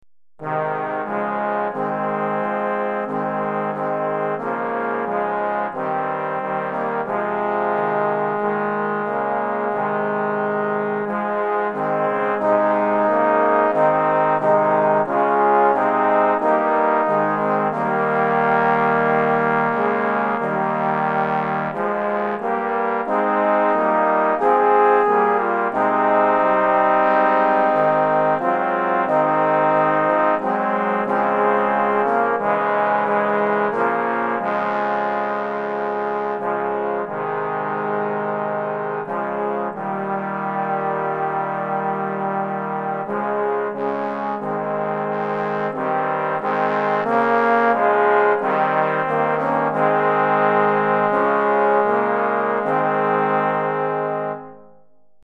4 Trombones